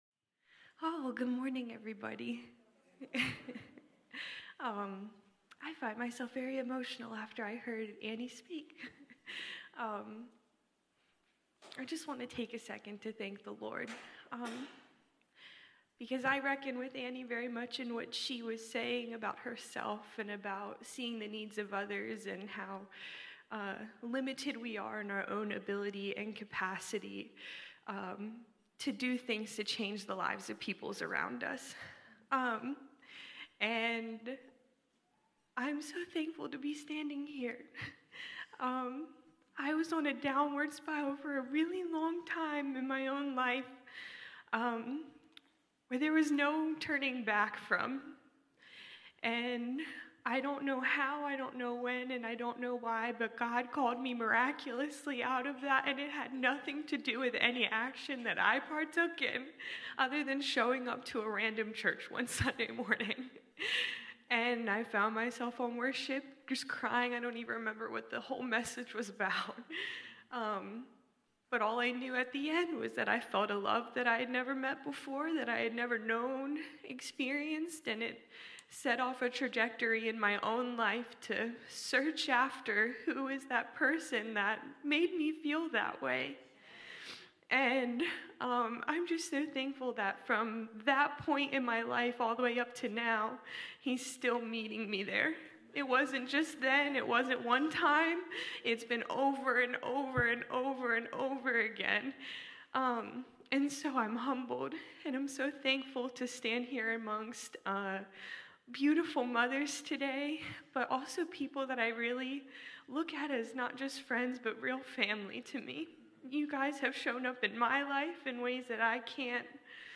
Sunday morning service, livestreamed from Wormleysburg, PA.